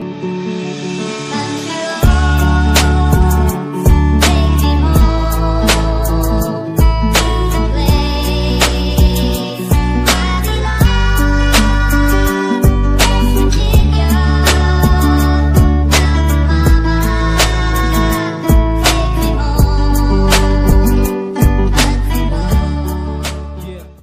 Acara Bakar Batu di Tanah Papua